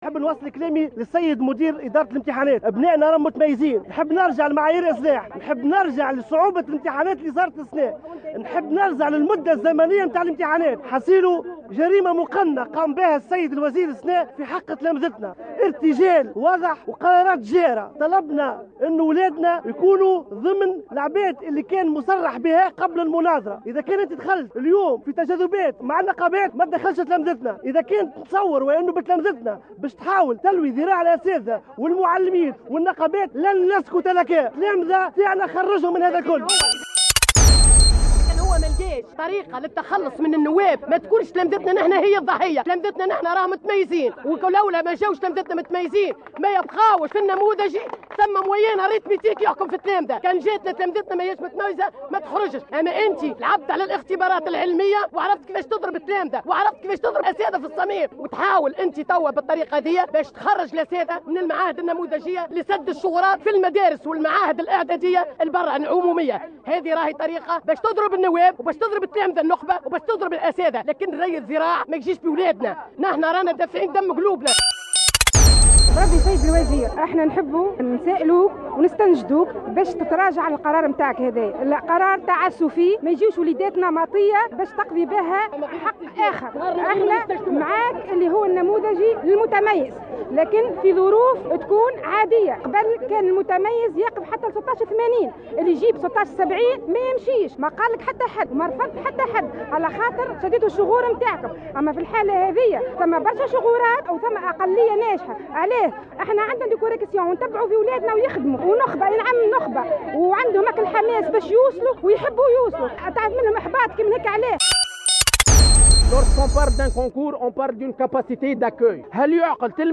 نفّذ صباح اليوم السبت عدد من الأولياء وقفة احتجاجية بساحة الحكومة بالقصبة، ضد قرار وزير التربية المتعلق بالحصول على معدل 15 من 20 لقبول التلاميذ بالاعداديات والمعاهد النموذجية.